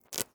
SFX_Harvesting_02.wav